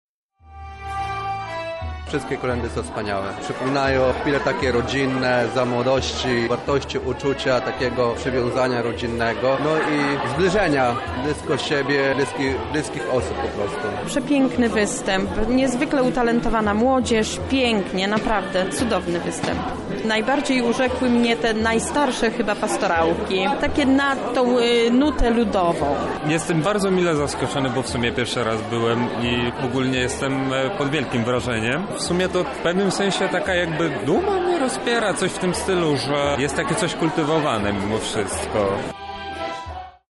Najstarszy zespół artystyczny UMCS zaprezentował bożonarodzeniowe pieśni. Za nami koncert kolęd w Chatce Żaka.